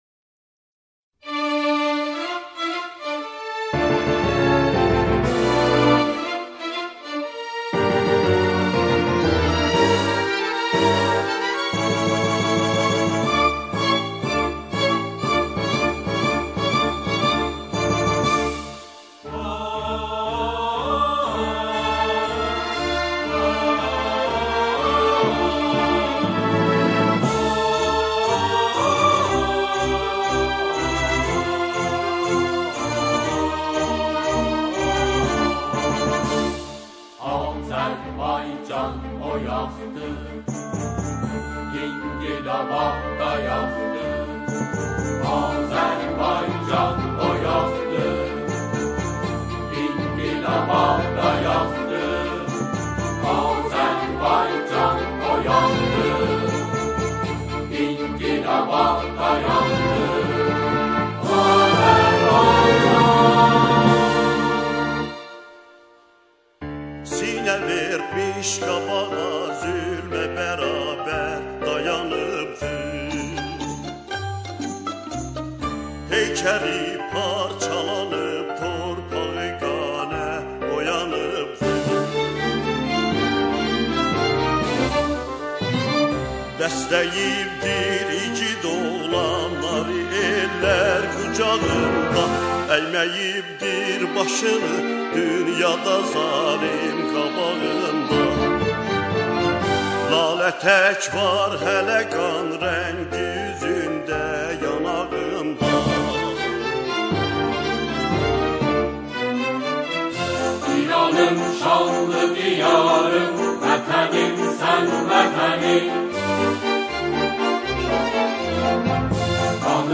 سرودهای شهرها و استانها